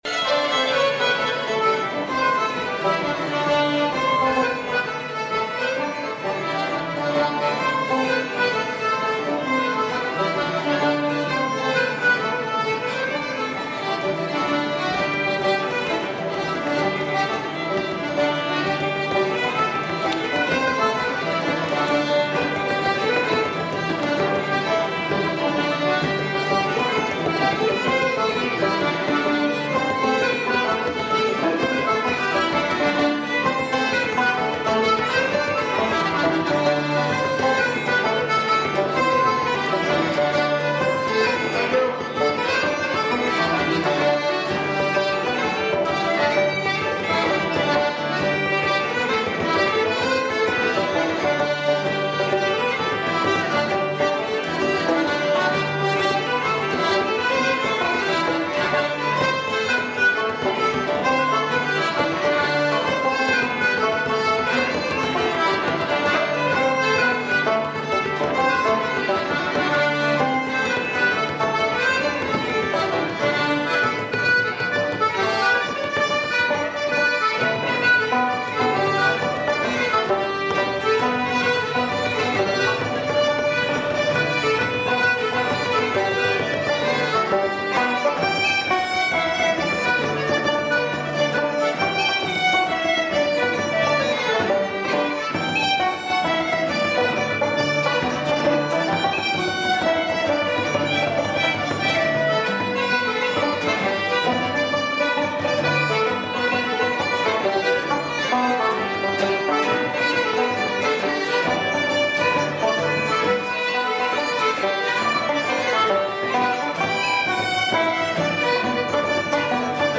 (2) On Cape Cod, a loosely organized group of folks who love to play Irish traditional music!
The group's instrumentation includes fiddle, guitar, flute, recorder, uilleann pipes, banjo, accordion, harmonica, concertina, bouzouki, whistle, mandolin, and bodhrán (the Irish frame drum). The group's repertoire consist of the Irish dance tunes - primarily reels, jigs, and hornpipes - that have been the staple of the Irish instrumental tradition for hundreds of years.
Cat's Melodeon is primarily an acoustic (non-amplified) group.